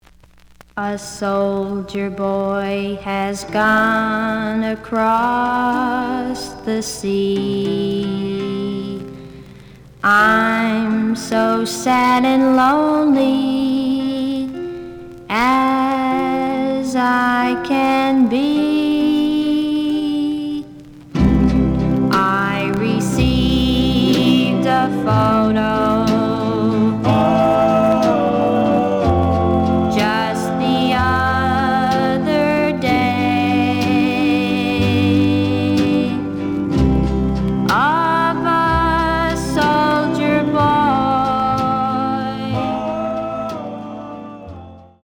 ●Genre: Rhythm And Blues / Rock 'n' Roll